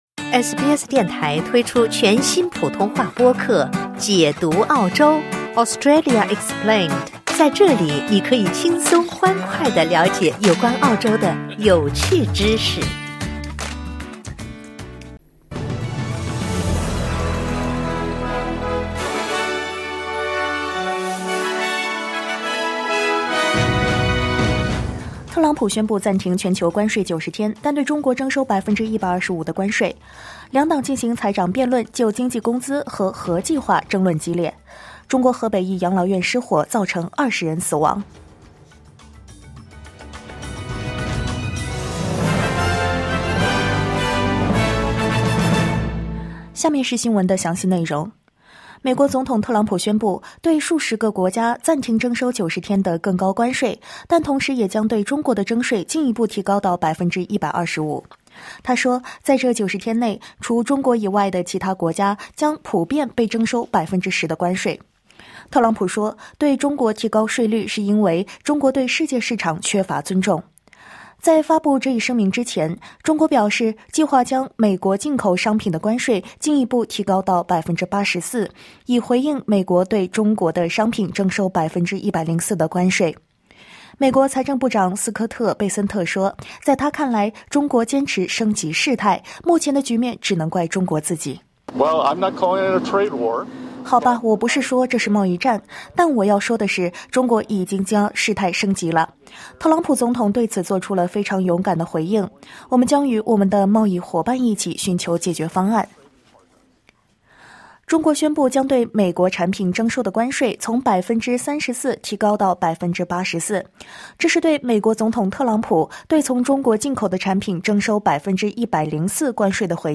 SBS早新闻（2025年4月10日）